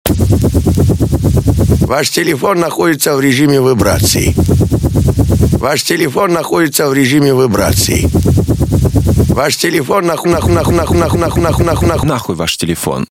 • Качество: 128, Stereo
забавные
смешные
Прикол на телефон